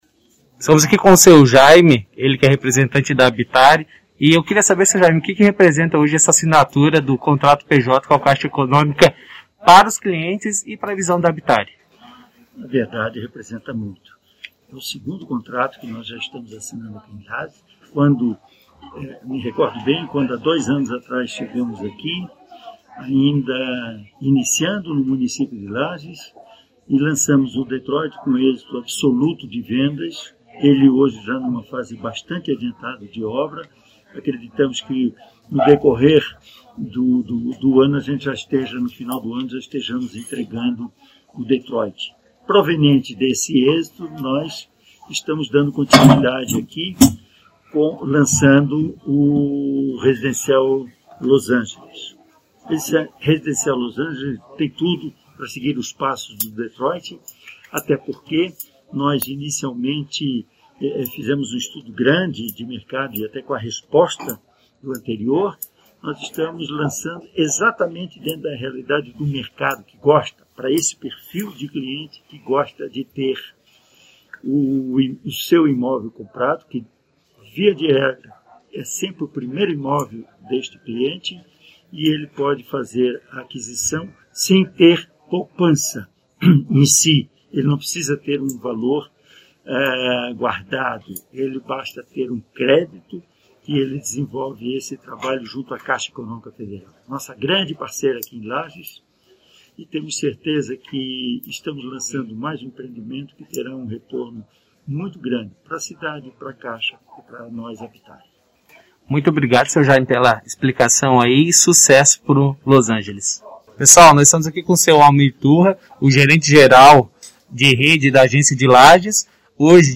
Entrevista-assinatura-contrato-com-a-Caixa.mp3